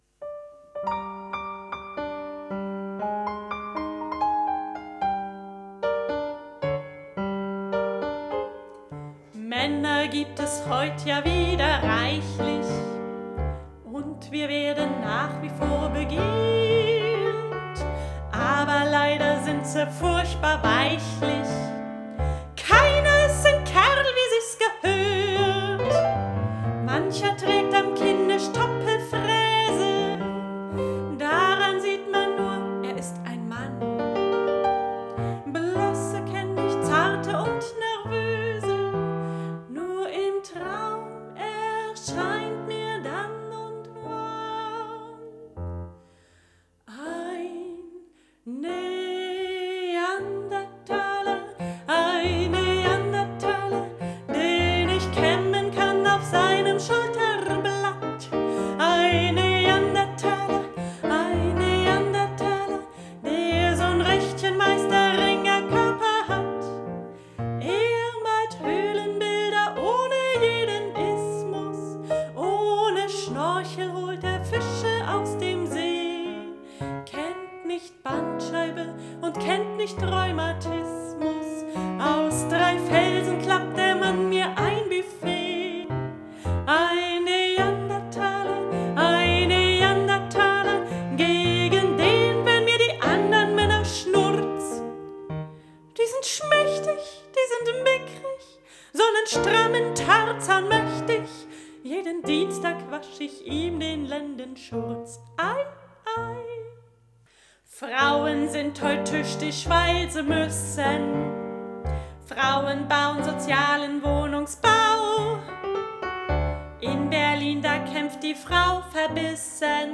Gesang, Klavier und mehr.